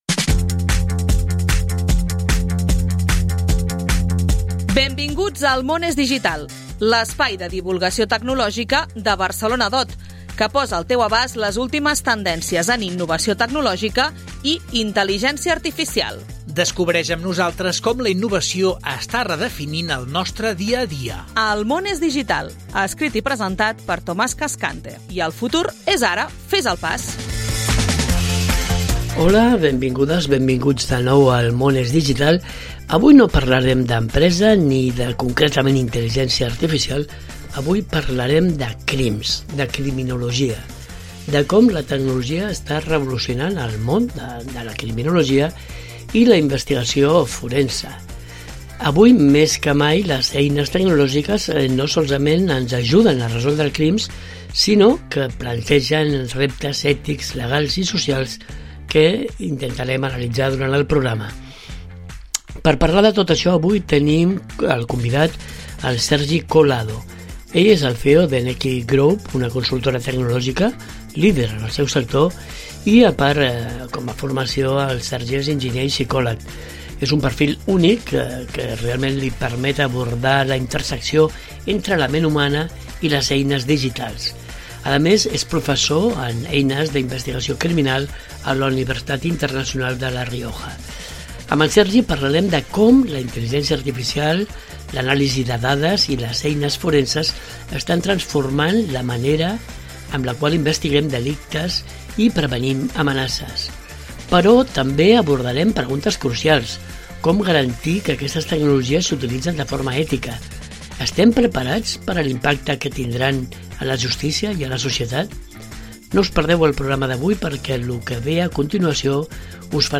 Cada setmana ens apropem a aquesta nova realitat a través de les entrevistes i les tertúlies amb destacats especialistes, directius, emprenedors i usuaris de les noves tecnologies.